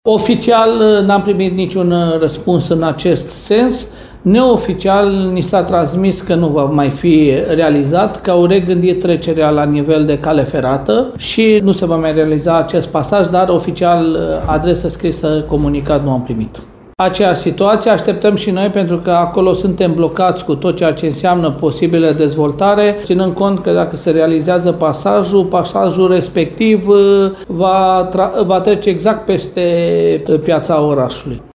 Primarul Lugojului, Călin Dobra, sublinează, însă, că este vorba doar de date neoficiale, atât despre pasajul ce ar fi urmat să fie ridicat pe strada Bocșei, cât și legat de cel de pe strada Oloșagului.